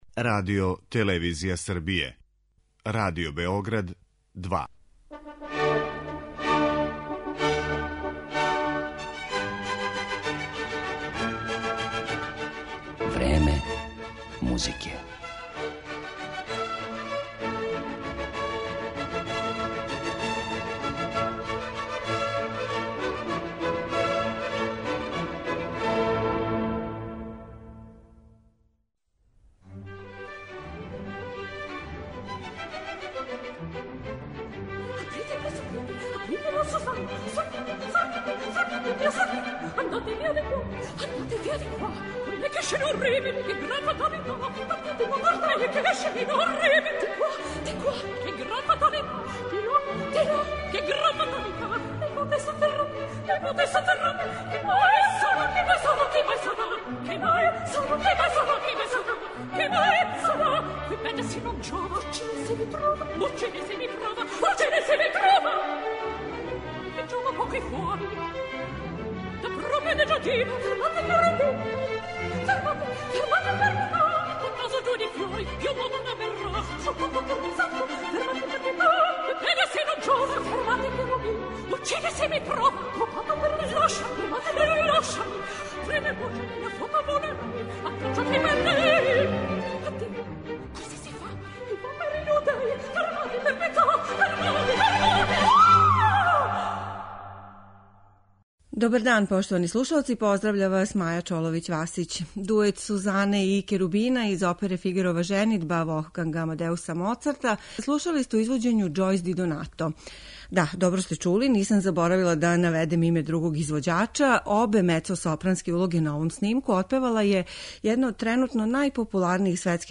барокним аријама